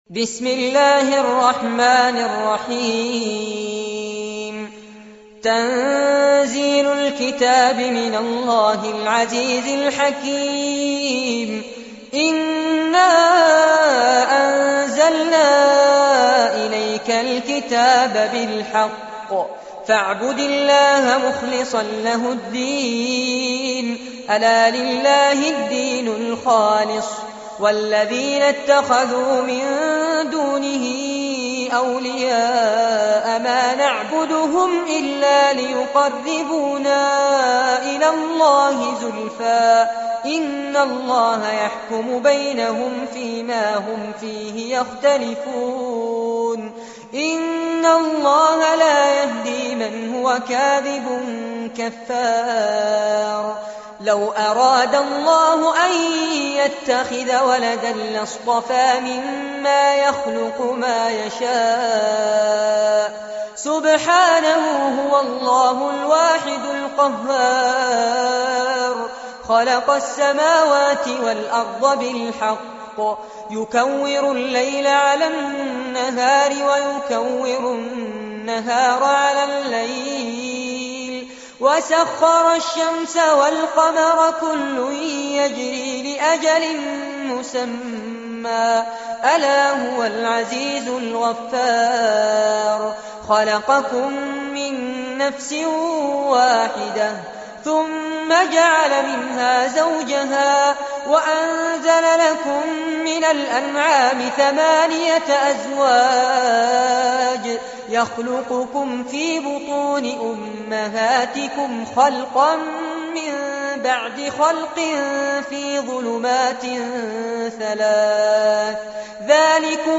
Surah Az-Zumar Recitation